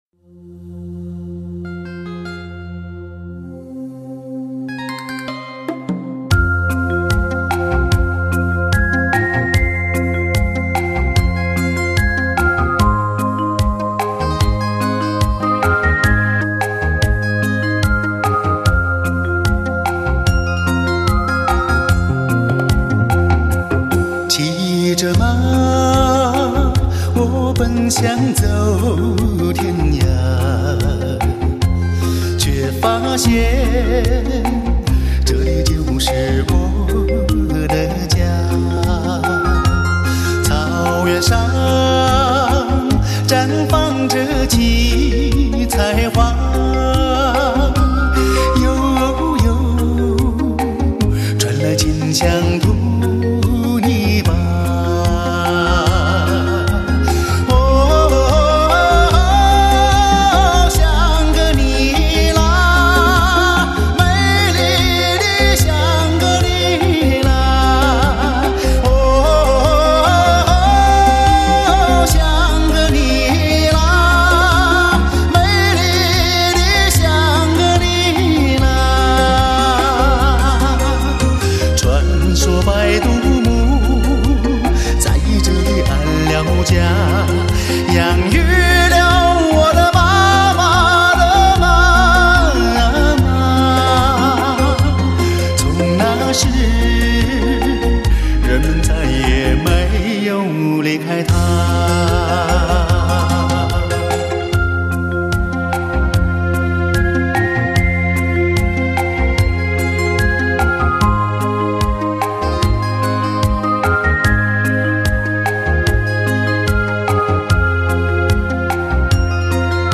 挑选最优秀的民族歌曲联手打造一部来自西藏高原的声音传奇。